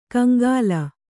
♪ kaŋgāla